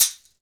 Index of /90_sSampleCDs/Northstar - Drumscapes Roland/PRC_Bonus Perc/PRC_Shakers x
PRC CAXIXI04.wav